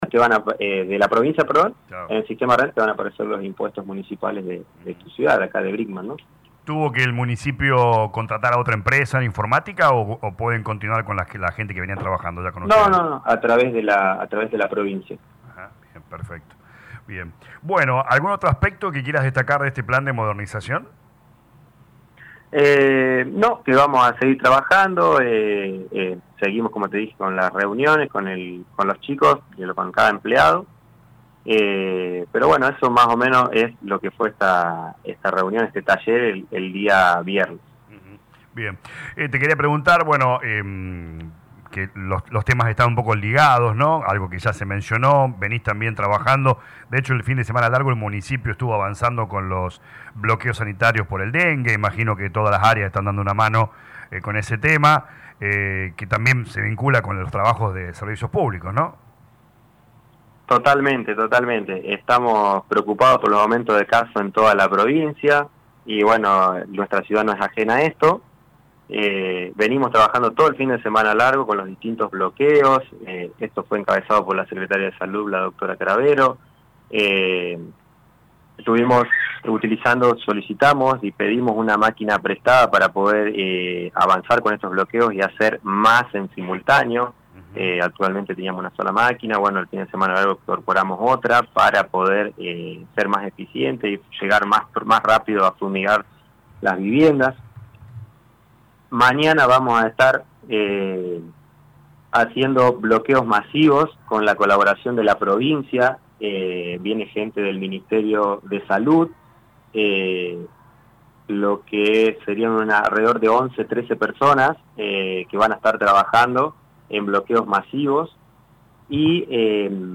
El Secretario de Gobierno Municipal Marcos Grande habló en LA MAÑANA DE LA RADIO sobre las distintas reuniones, talleres y capacitaciones que se vienen realizando en el marco del Plan de Modernización Municipal.